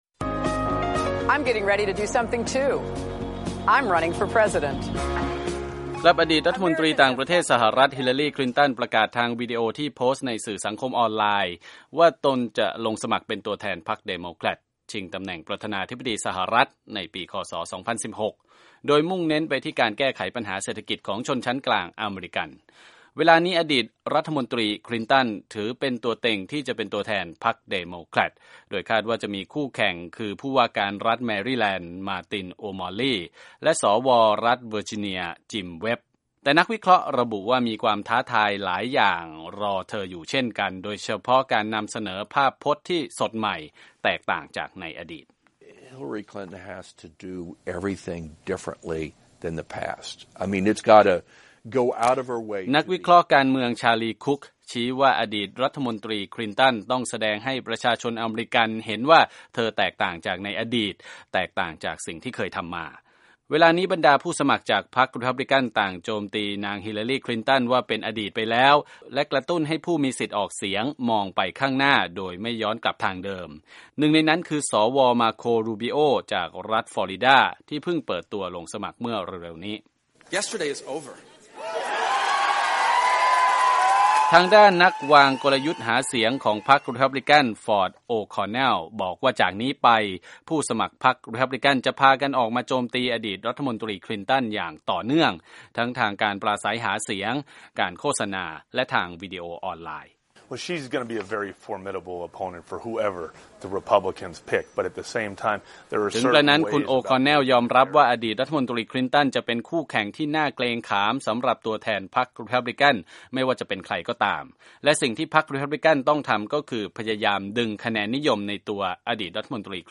รายงานจากห้องข่าววีโอเอ